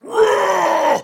Звуки орков
Орк яростно воскрикнул